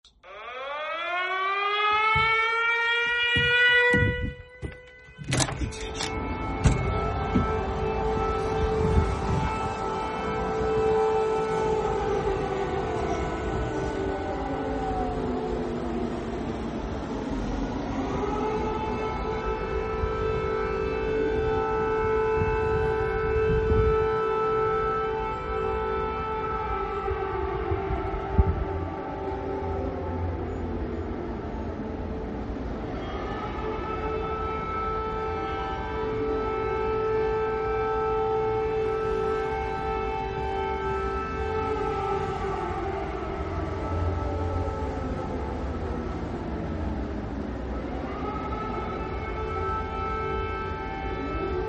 Así es el sonido de las sirenas en Ucrania